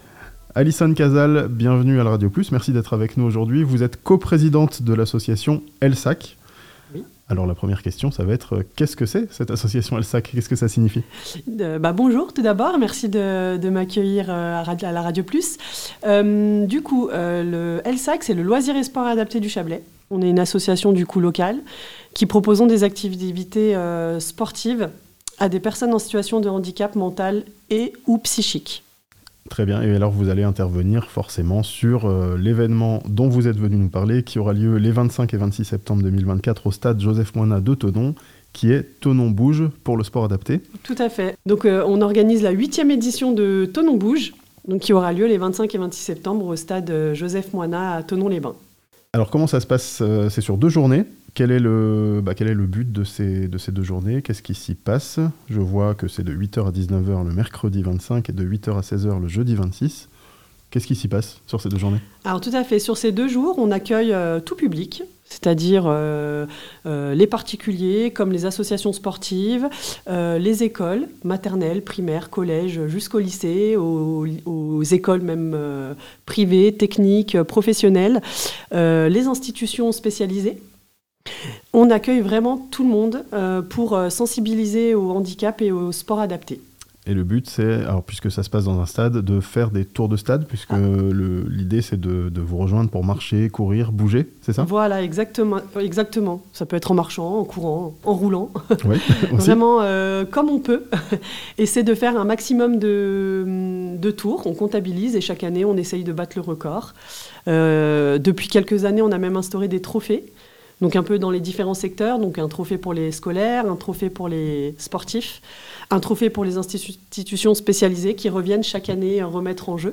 Thonon bouge les 25 et 26 septembre, pour le sport adapté (interview)